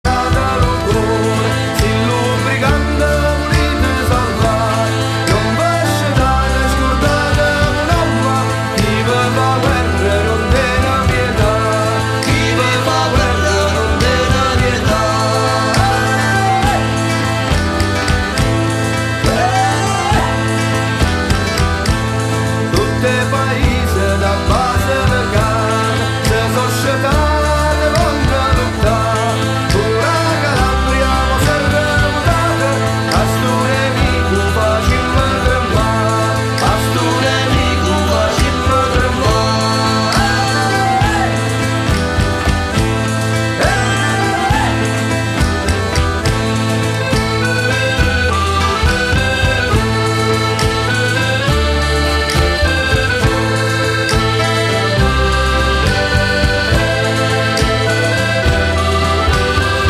Voce e Chitarra
Fisarmonica
Sax e Clarinetto
Percussioni